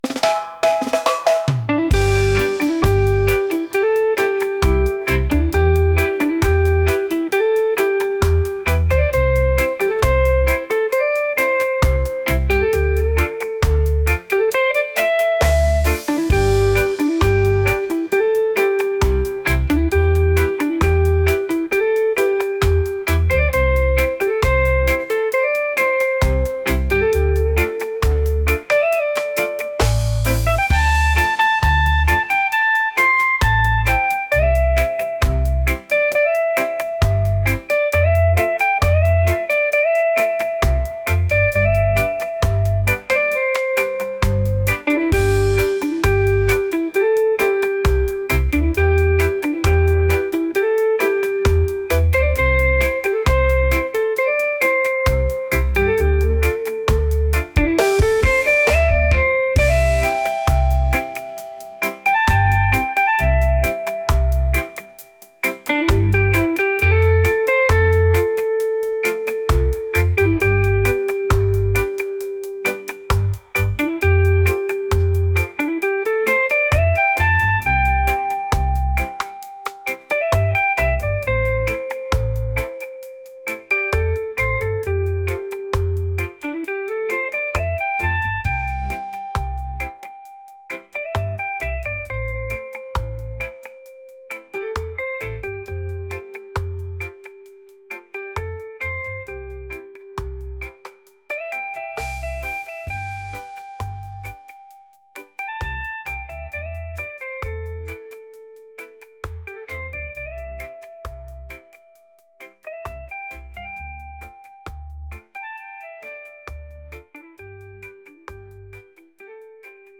soulful | reggae